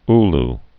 (l) or u·lo (-lō)